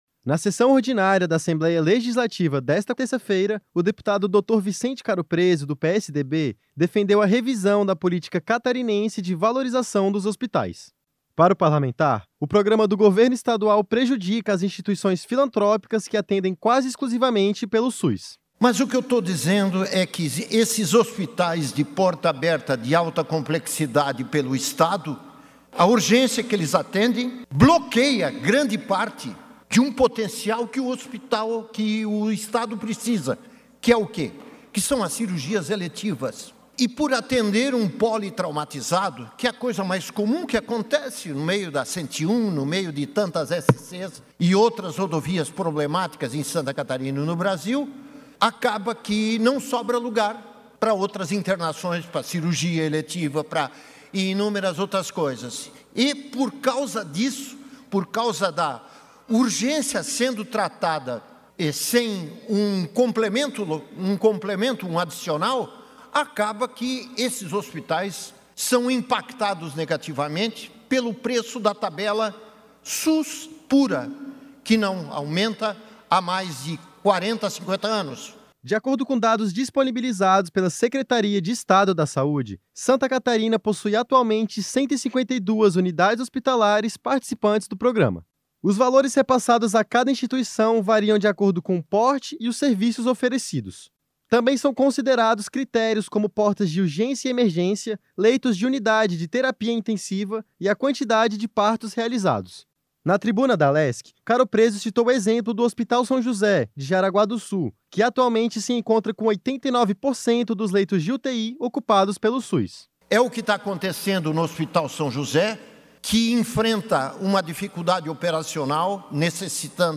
Na sessão ordinária da Assembleia Legislativa desta terça-feira (18), o deputado Dr. Vicente Caropreso (PSDB) defendeu a revisão da política catarinense de valorização dos hospitais.
Entrevista com:
- deputado Dr. Vicente Caropreso (PSDB).